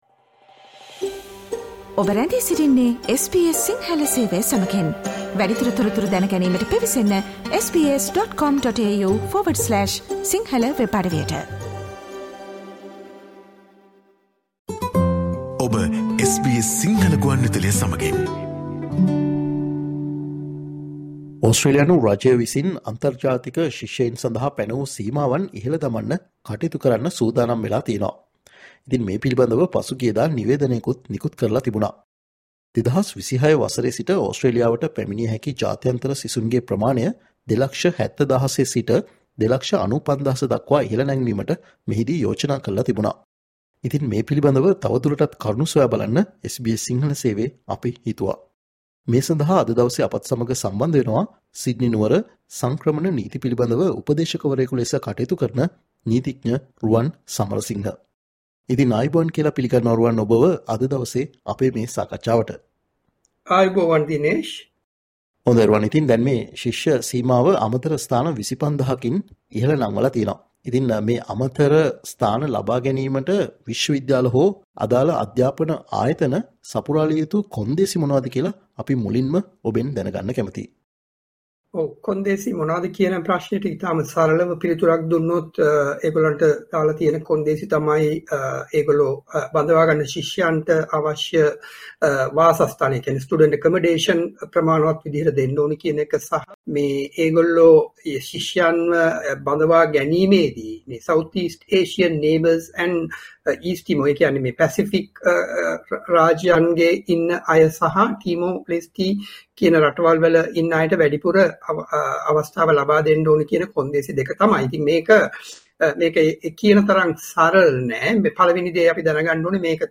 මේ හරහා ශිෂ්‍ය වීසා ලබාදීමේ ක්‍රියාවලියට සිදුවන බලපෑම සහ ඕස්ට්‍රේලියාවේ මේවනවිට අධ්‍යාපනය ලබන සිසුන්ට මේ හරහා යම් බලපෑමක් සිදුවන්නේද වැනි කාරණා පිළිබඳව SBS සිංහල සේවය සිදුකල සාකච්චාවට සවන්දෙන්න